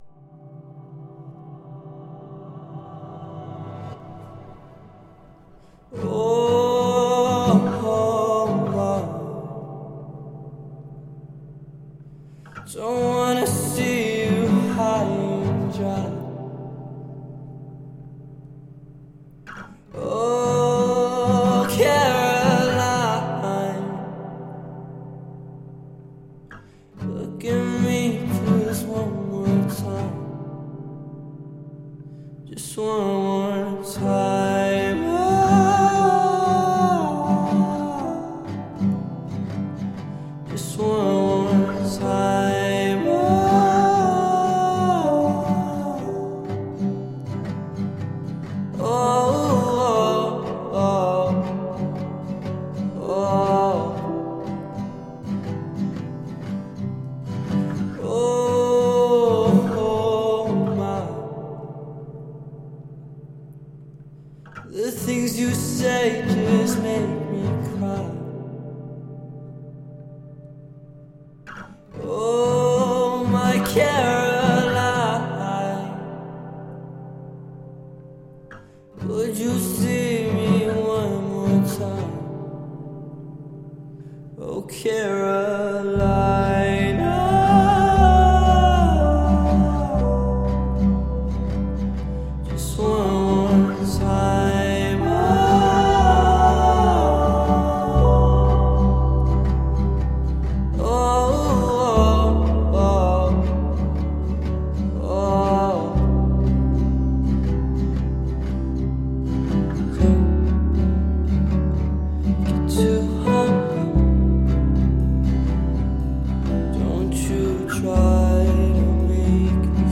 موسیقی خارجی
آهنگ غمگین